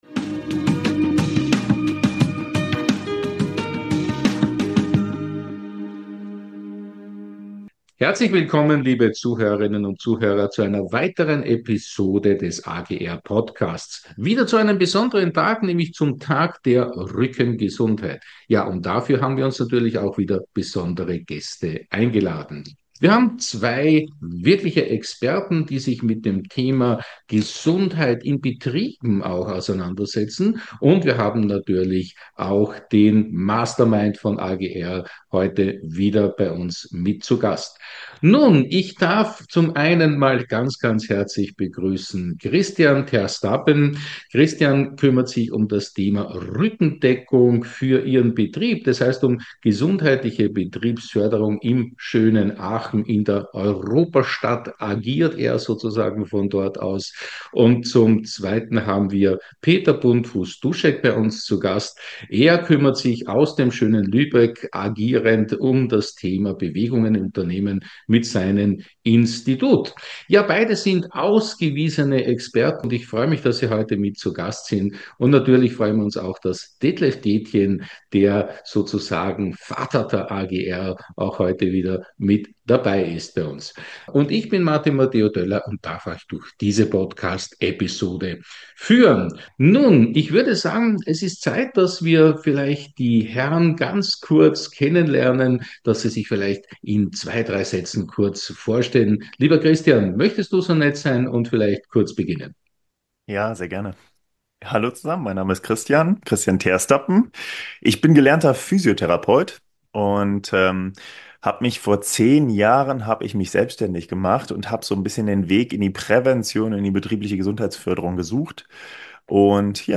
Doch wie gelingt das, wenn der Nacken spannt und der Terminkalender aus allen Nähten platzt? Im AGR-Podcast sprechen wir mit drei Fachleuten, die sich täglich mit diesem Thema auseinandersetzen